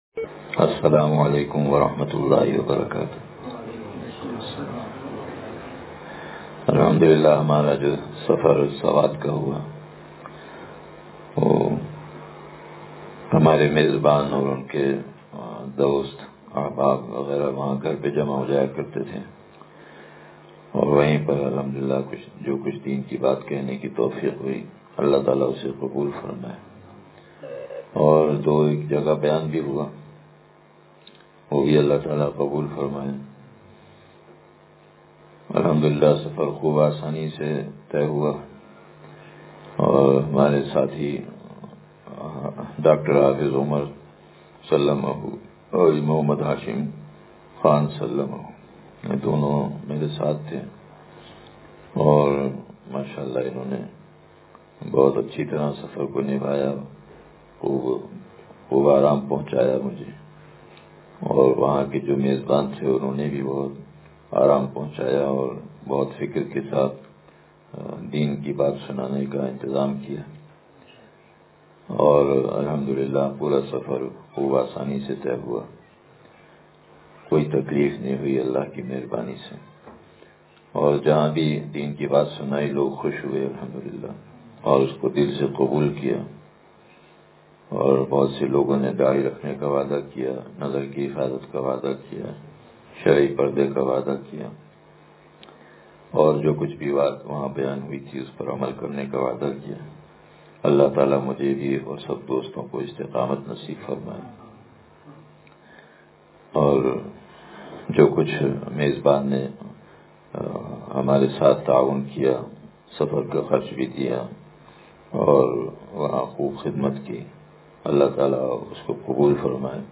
مقصدِ سلوک (تشریح حضرت میر صاحب رحمت اللہ علیہ) – سوات کے سفر سے واپسی پر بیان